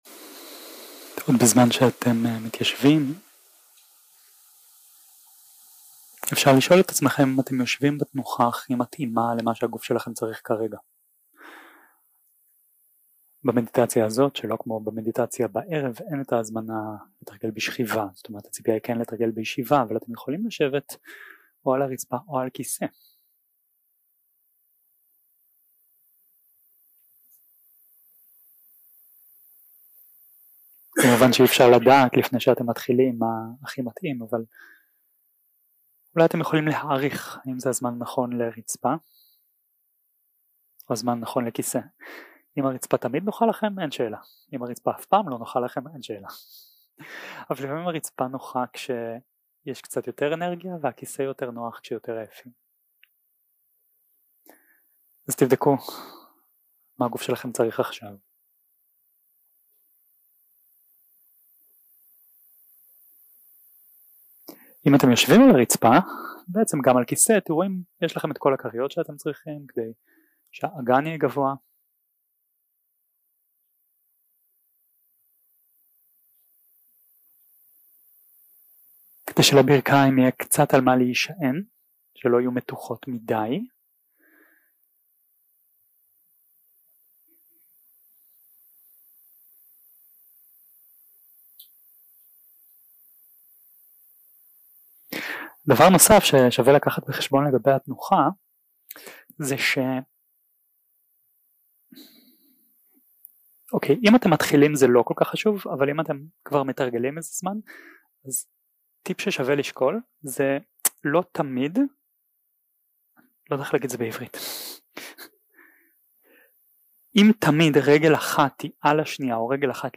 יום 3 – הקלטה 6 – צהריים – מדיטציה מונחית
Dharma type: Guided meditation